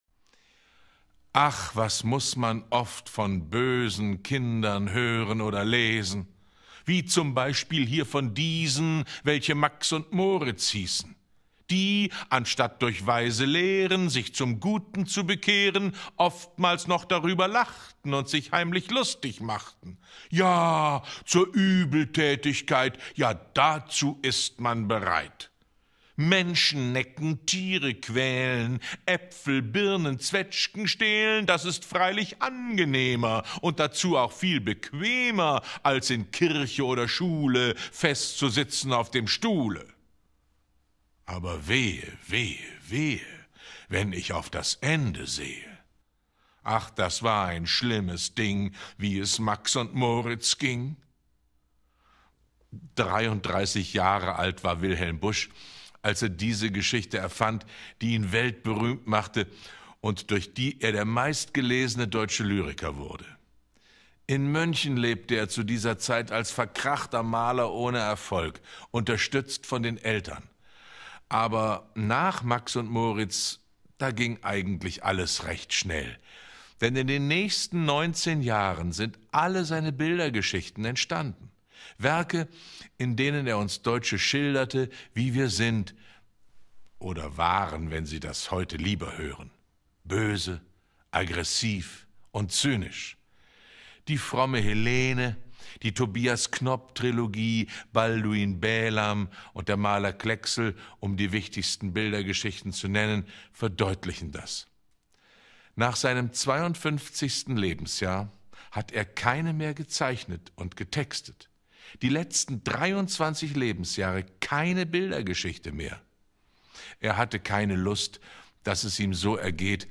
6. Livestream aus dem Nelfenturm vom 02.07.2011